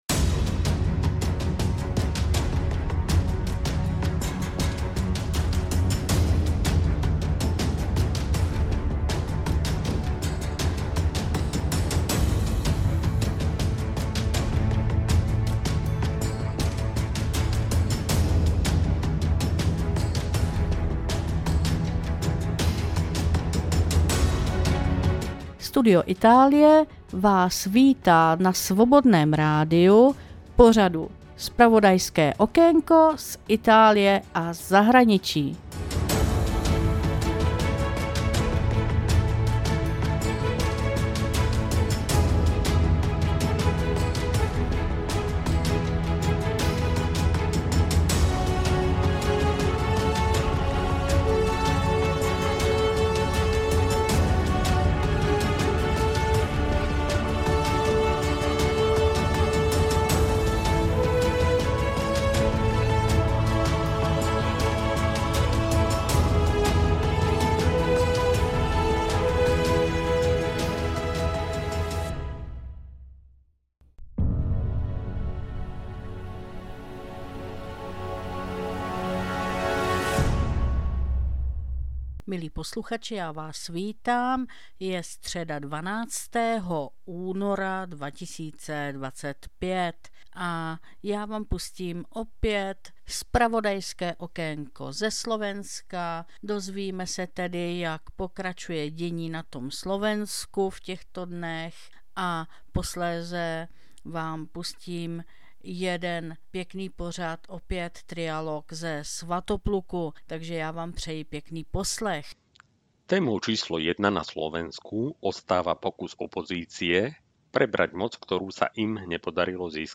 2025-02-12 – Studio Itálie – Zpravodajské okénko ze Slovenska – Trialog zakladatelů spolku Svatopluk o aktuálních událostech